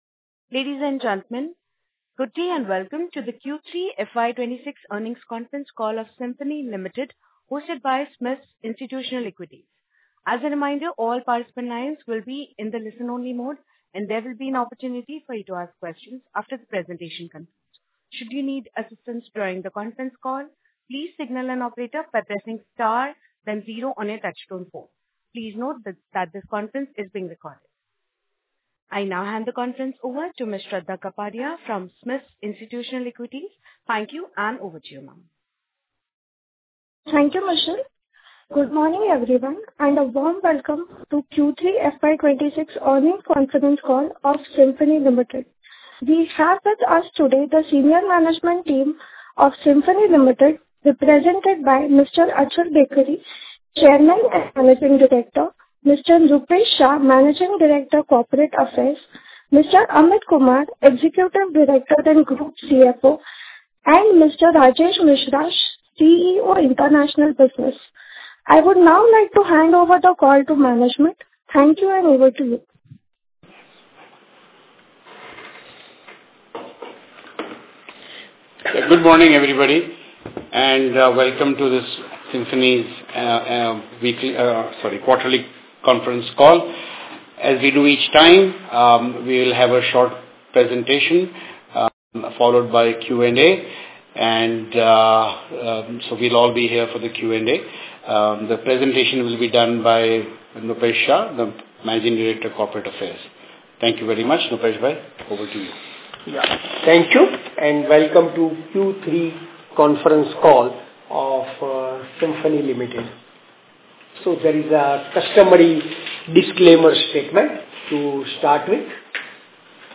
Concalls
Recording_Q3FY26-Earnings-Conference-Call.mp3